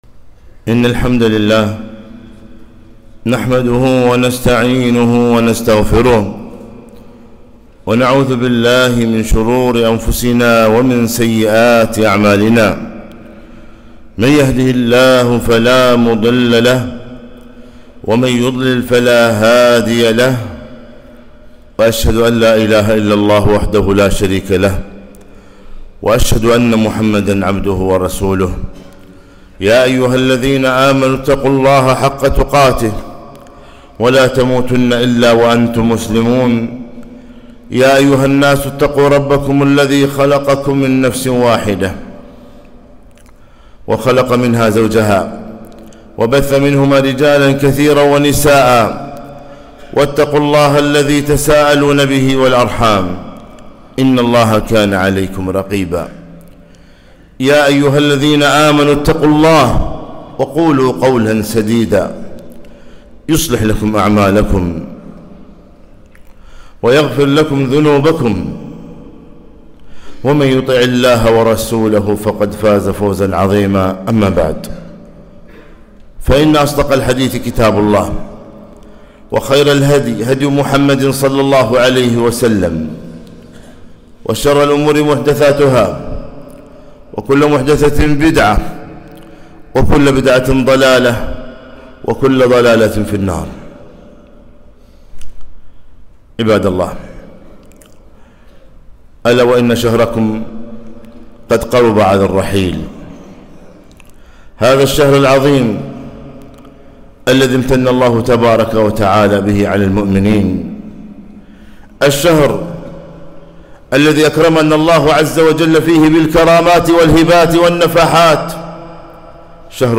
خطبة - ختام رمضان